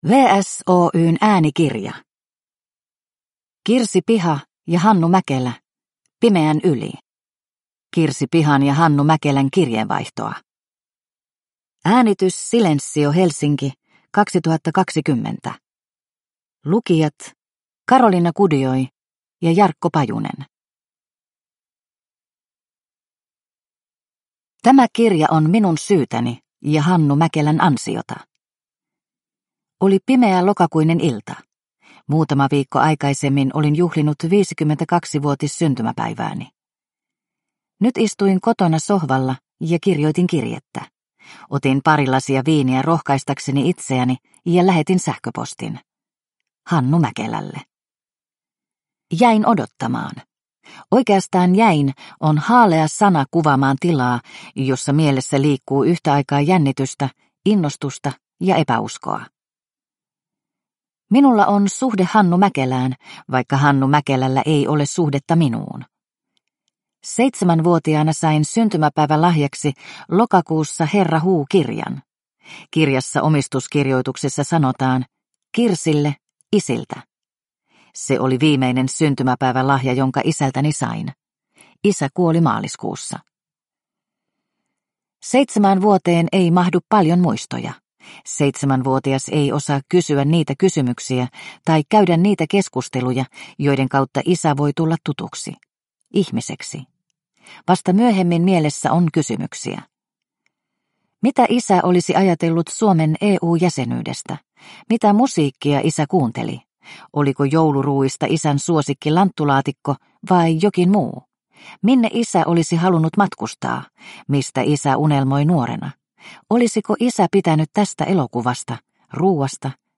Pimeän yli – Ljudbok – Laddas ner